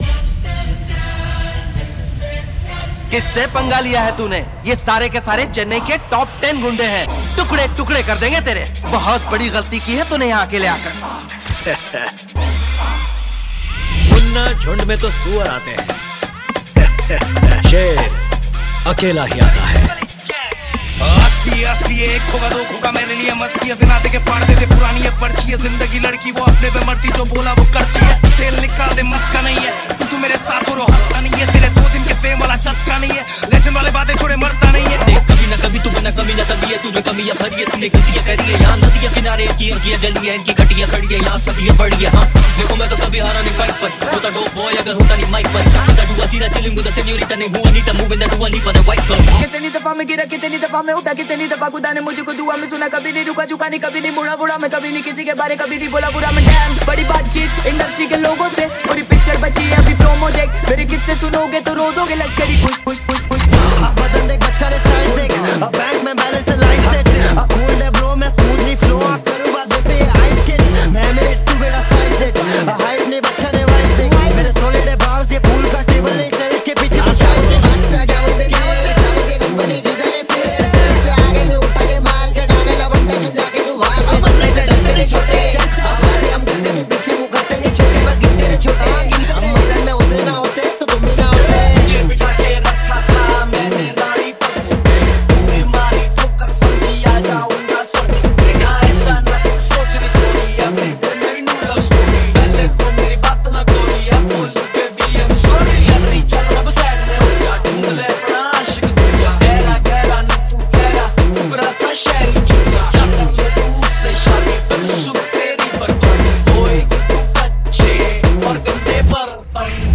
Remix